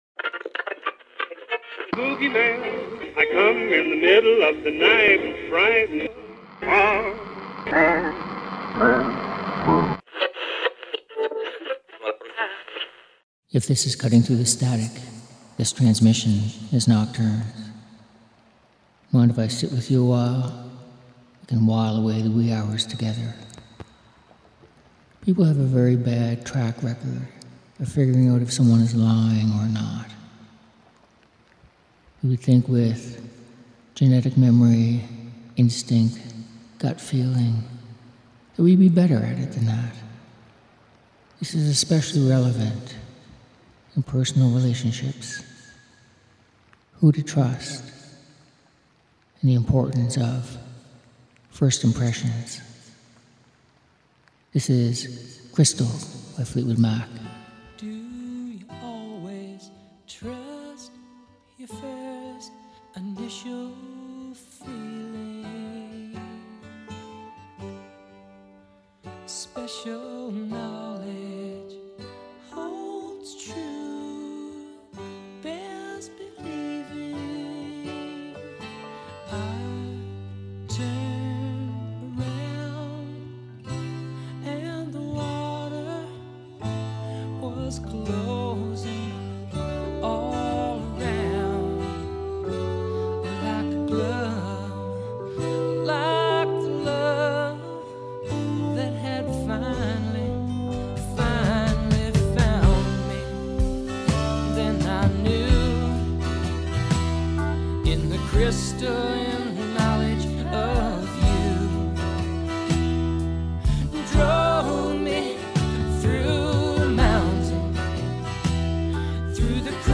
Music For Nighttime Listening